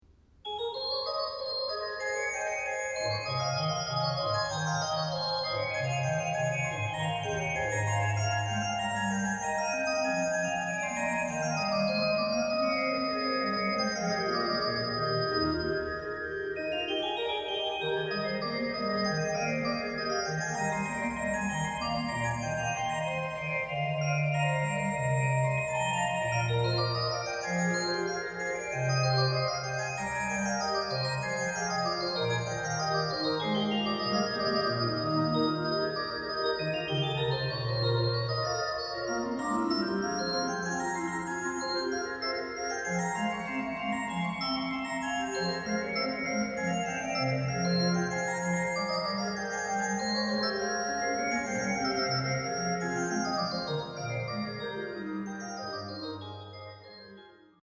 What better instrument to play it on other than the splendid new Bach organ in Thomaskiche Leipzig.
Registrations are clear, incisive and perfectly balanced, full of colour and show off the wonderful voicing of the instrument.